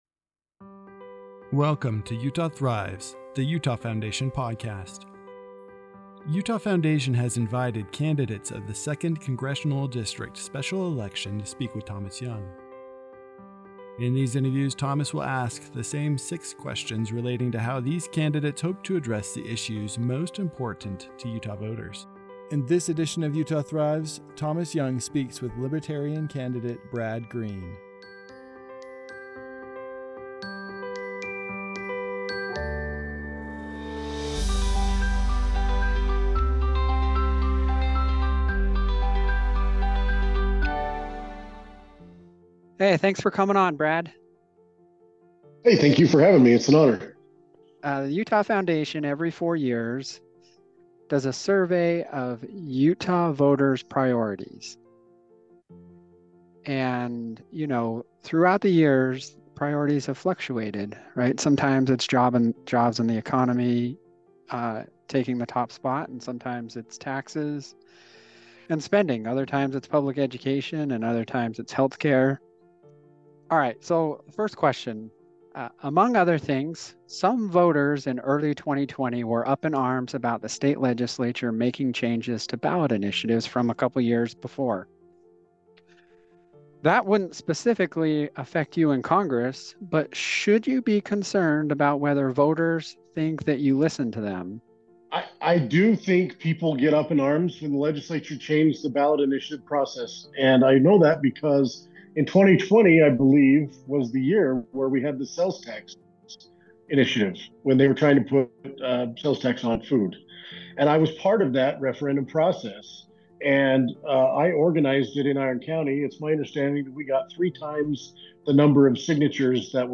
This interview was edited for brevity.